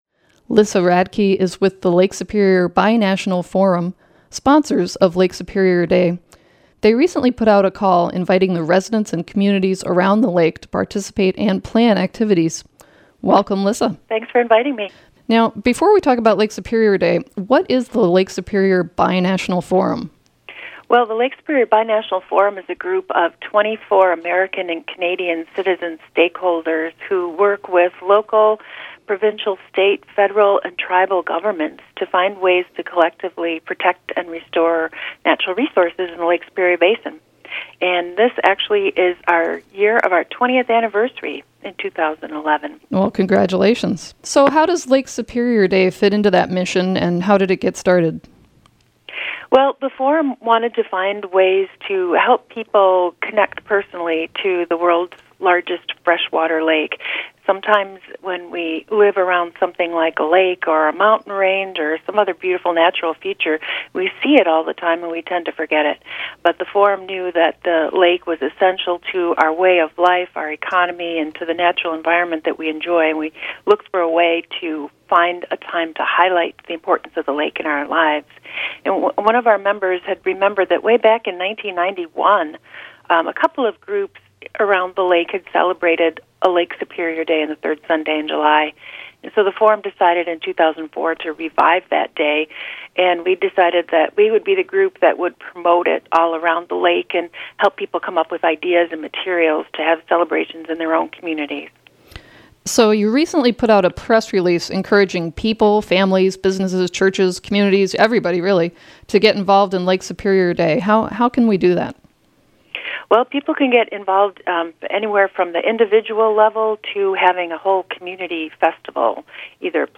and in this interview